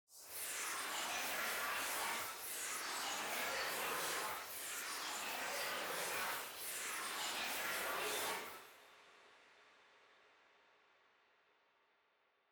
Reverieの最大の特徴は、「ブダペスト・スコアリング・クワイア」と共に録音されていることです。
美しいクワイアサウンドから実験的な音響表現まで、その多彩な表現力を実際にいくつかのプリセットで聴いてみてください。
このように、神秘的な合唱の美しさに加えて、CUBEならではの実験的な加工が施されたプリセットも収録されています。